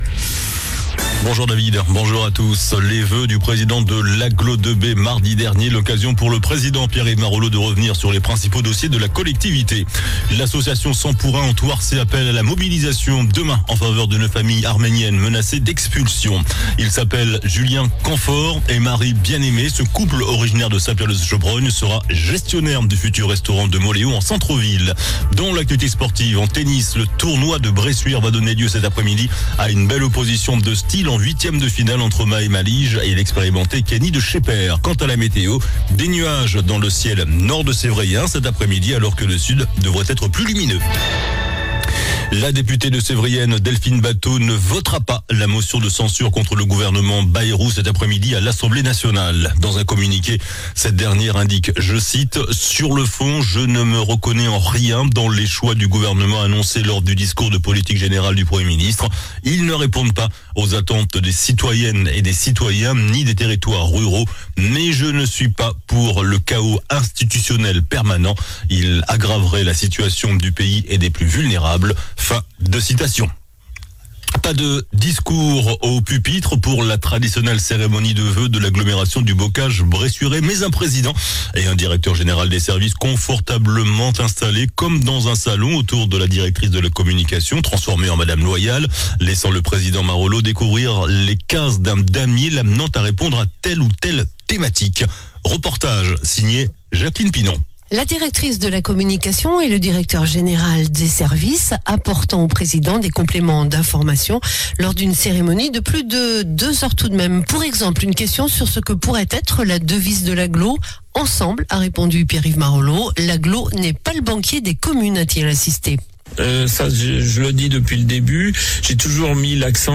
JOURNAL DU JEUDI 16 JANVIER ( MIDI )